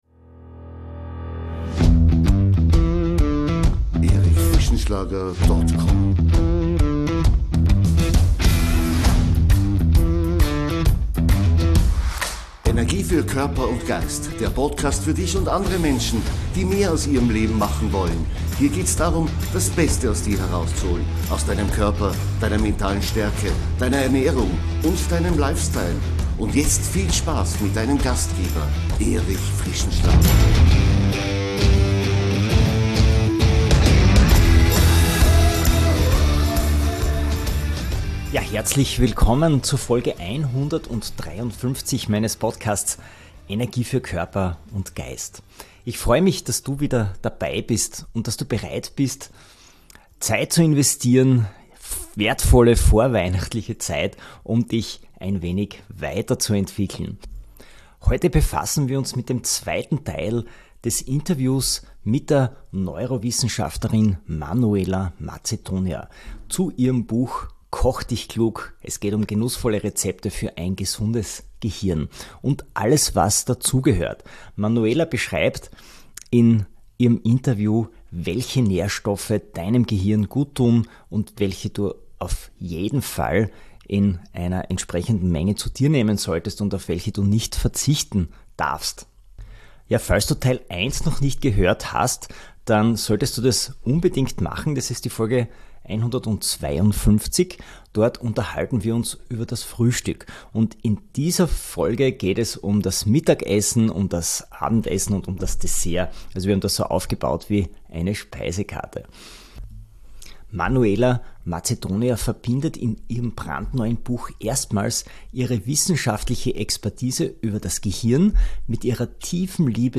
Im zweiten Teil unseres Gesprächs